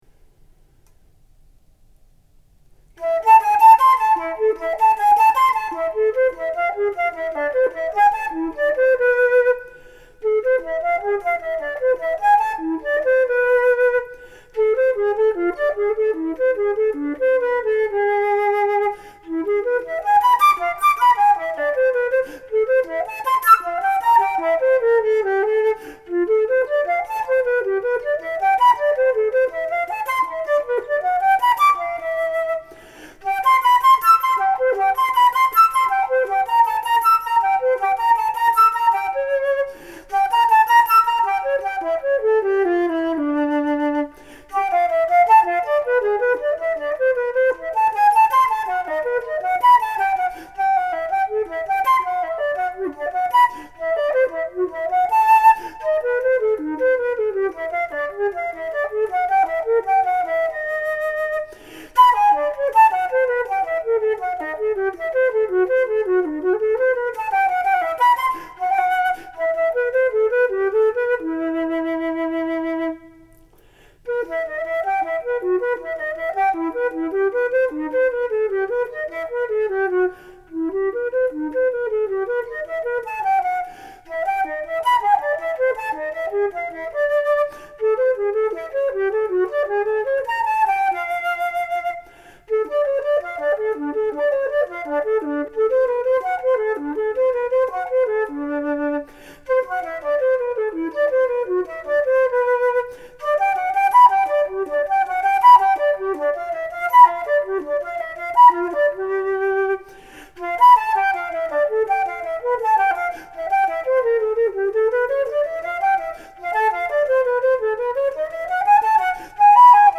SOLO RECORDINGS
Flute.